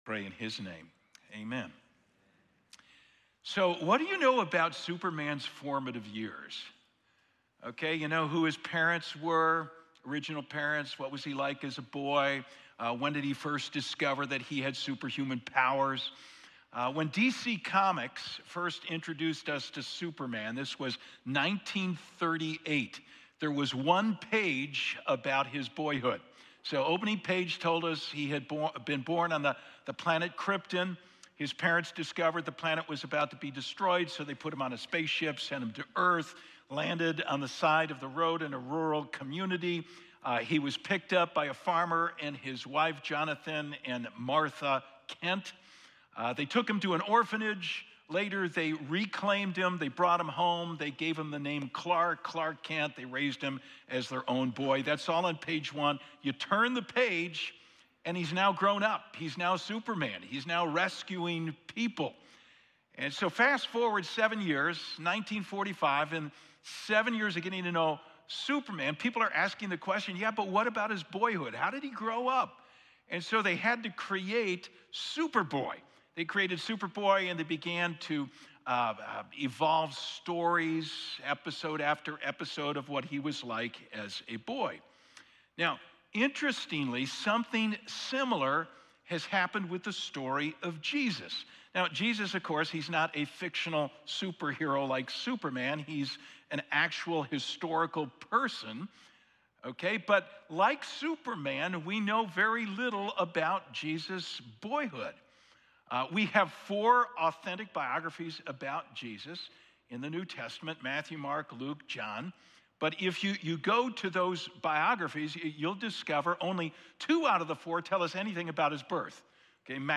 12-1-24-SErmon.mp3